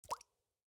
drip_water_cauldron5.ogg